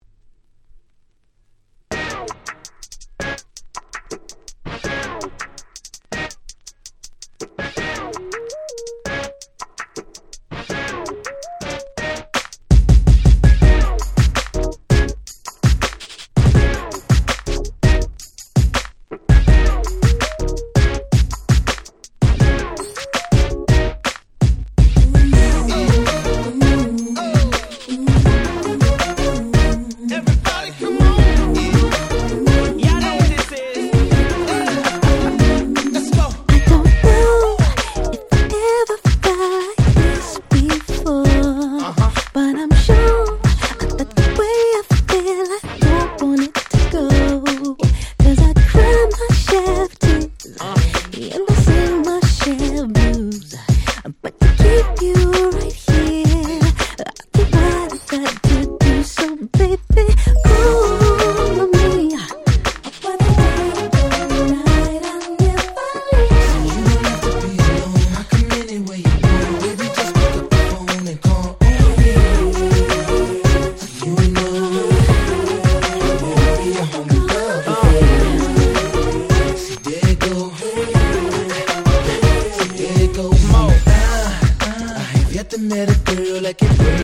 06' Super Hit R&B !!
「Dilemma」の再来か！？とまで言われた甘い甘ーい素敵な1曲！